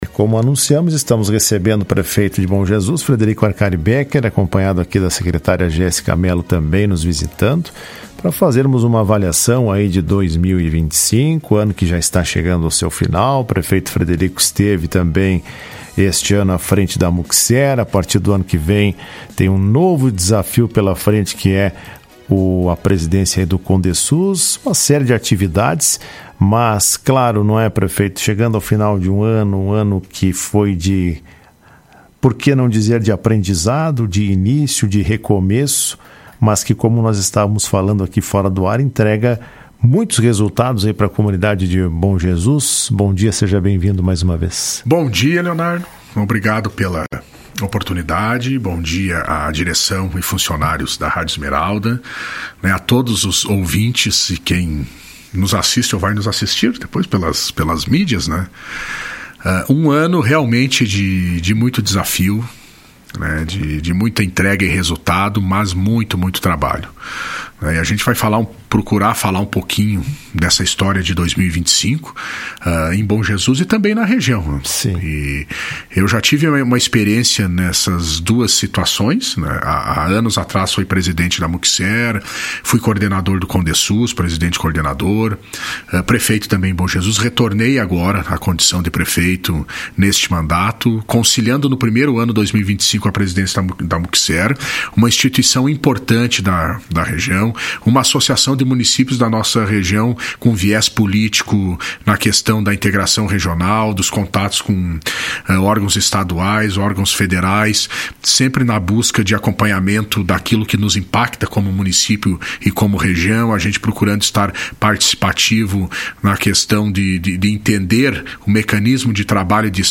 Ele participou do programa Comando Geral desta terça-feira e fez uma avaliação do trabalho realizado. Segundo Becker, 2025 foi um ano de muitos desafios, trabalho e resultados.